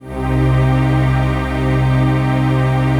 DM PAD2-67.wav